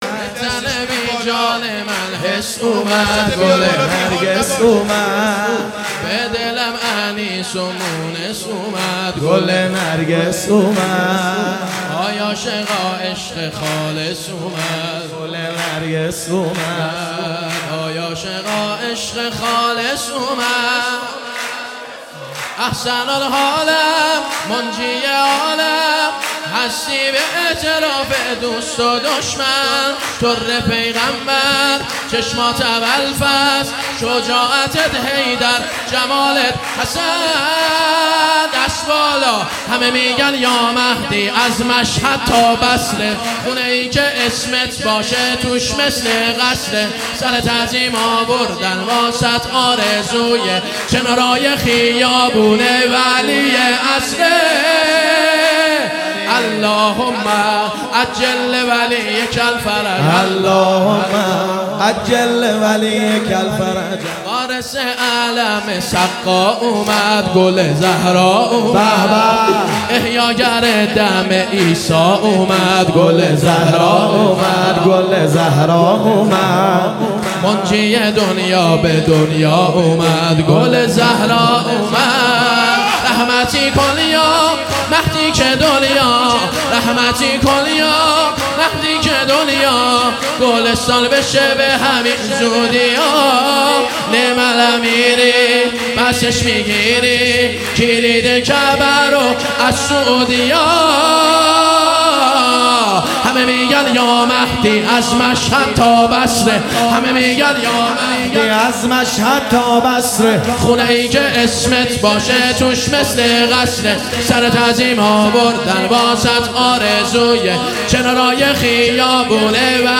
مولودی نیمه شعبان
روضه مقدس آل یاسین تهران